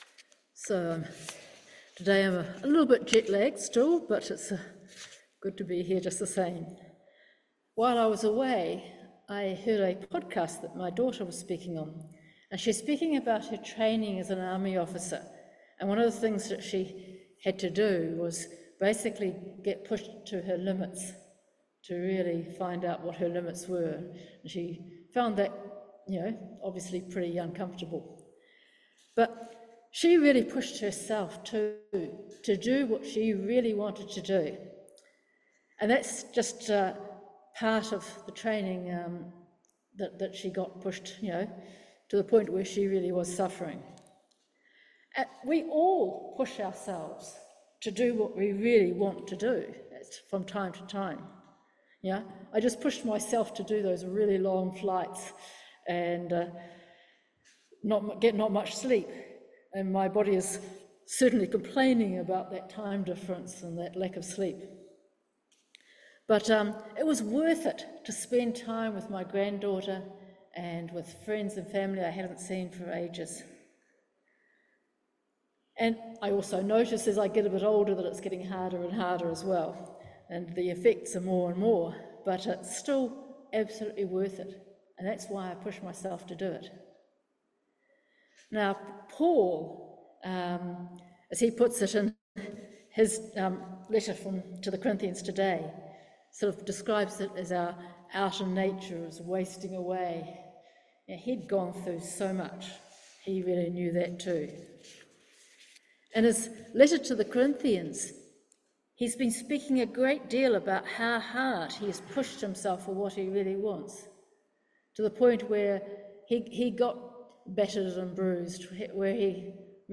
Passage: 2Corinthians 4:13-5:1 Service Type: Holy Communion Download Files Notes Topics
Sermon-9-June-.mp3